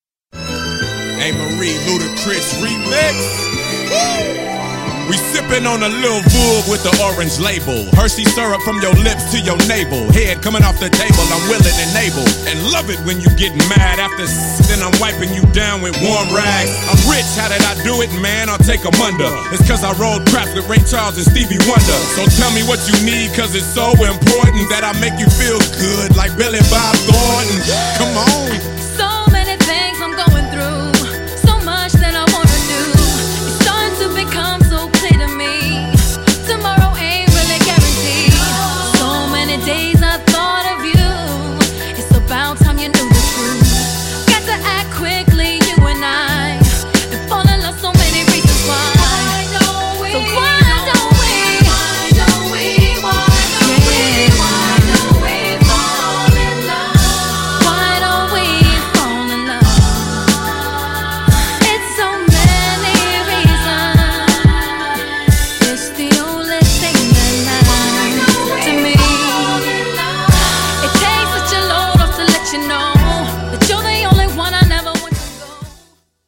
GENRE R&B
BPM 91〜95BPM
メロウR&B
哀愁系 # 女性VOCAL_R&B